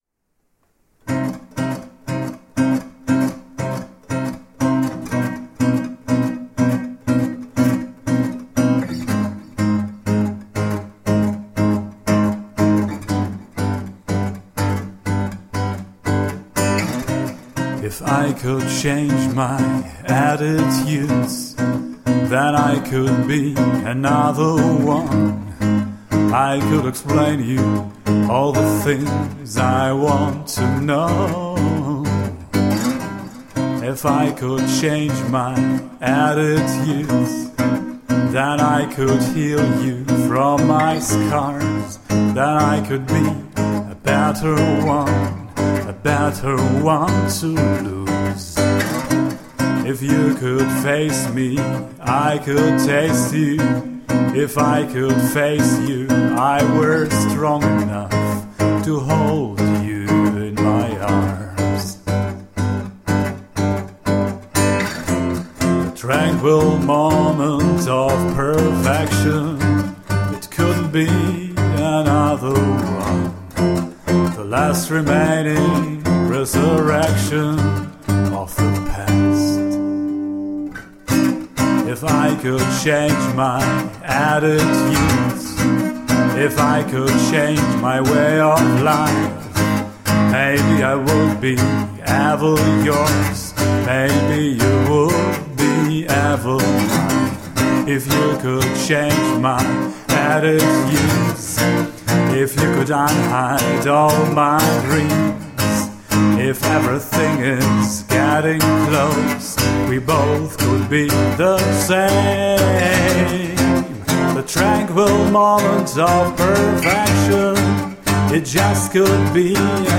Pure soulful and raw acoustic songwriter.
Tagged as: Alt Rock, Darkwave, Goth, Indie Rock